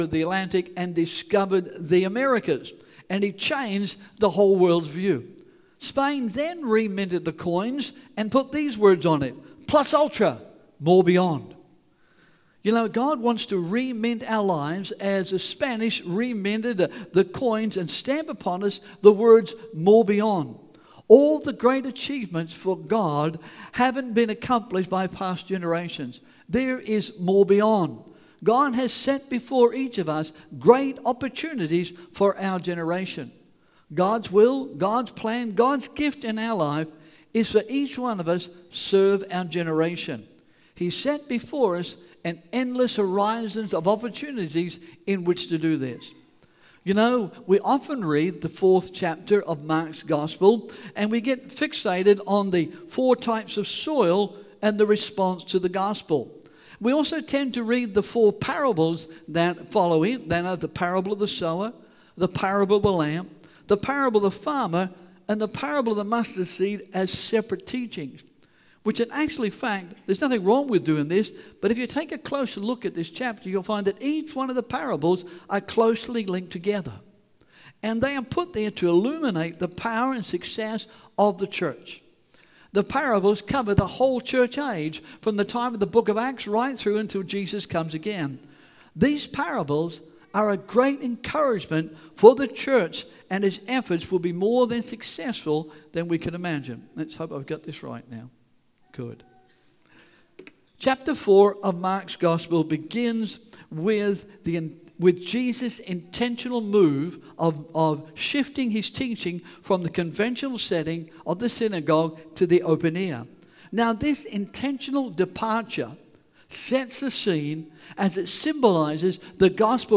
English Sermons | Casey Life International Church (CLIC)
English Worship Service - 4th September 2022